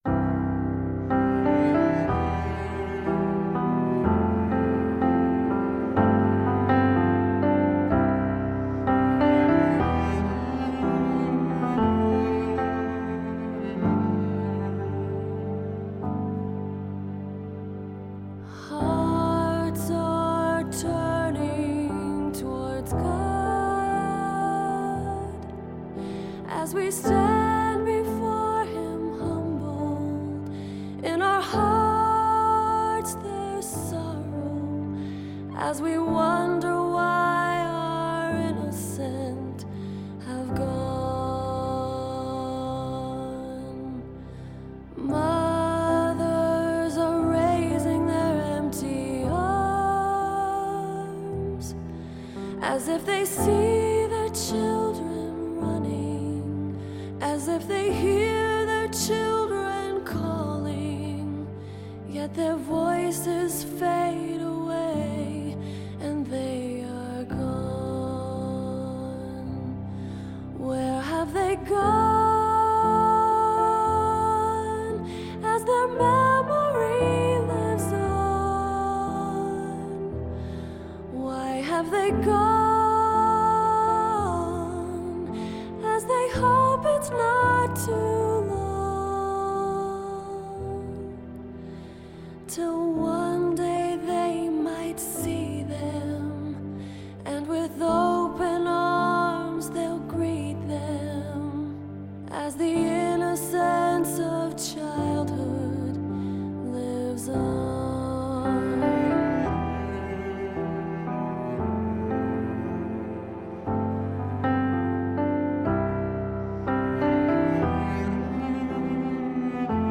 配樂中強烈的情感表達源自一支管絃樂隊的配樂與雅致的鋼琴平穩的展開。以及真切的爵士韻律的鼓奏，吉他與低音樂器。